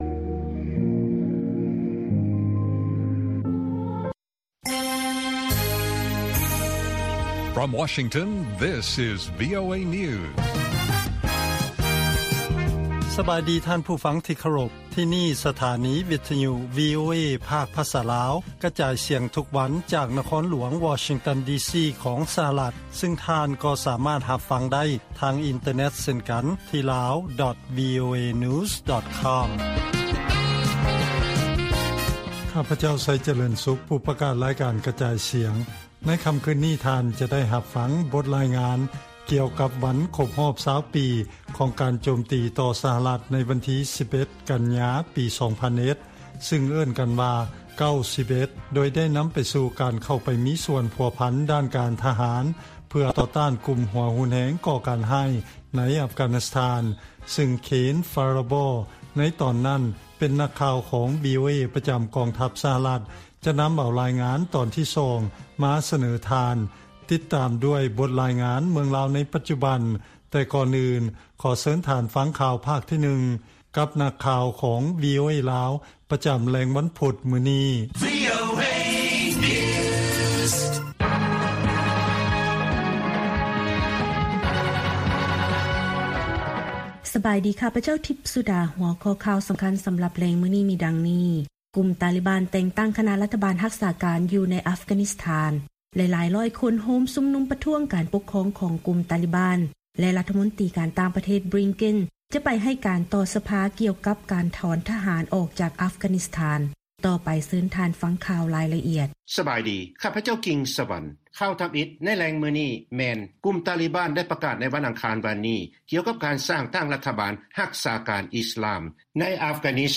ລາຍການກະຈາຍສຽງຂອງວີໂອເອ ລາວ: ໄຟໄໝ້ເຮັດໃຫ້ພວກນັກໂທດ ທີ່ຖືກຈຳຄຸກຢ່າງໜ້ອຍ 40 ຄົນ ເສຍຊີວິດຢູ່ອິນໂດເນເຊຍ